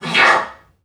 NPC_Creatures_Vocalisations_Robothead [13].wav